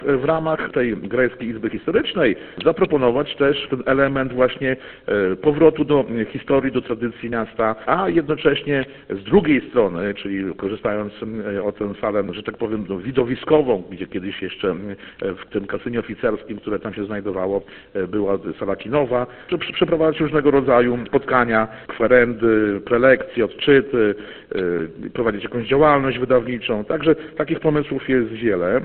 – Renowacji doczeka się również elewacja – mówi Dariusz Latarowski, burmistrz Grajewa.